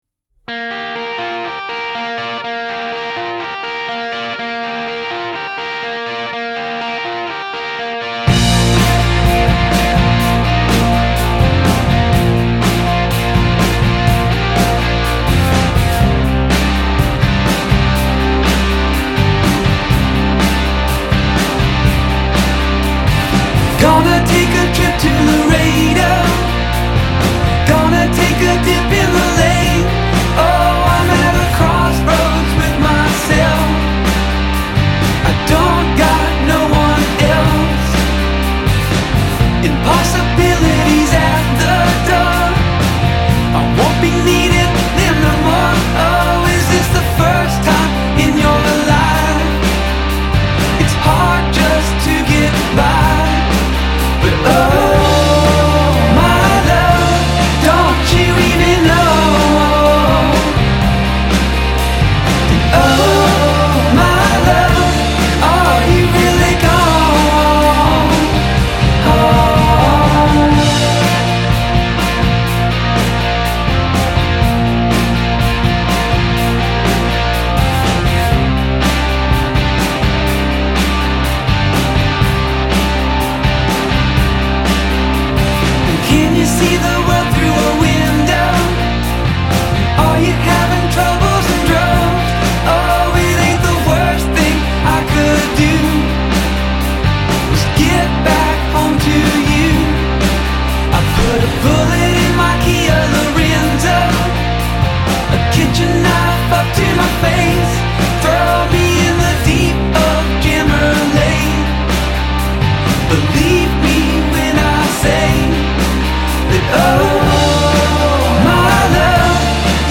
a Old Western?Mexico/Desert vibe